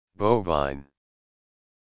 Audio Pronunciation of Bovine
bovine.mp3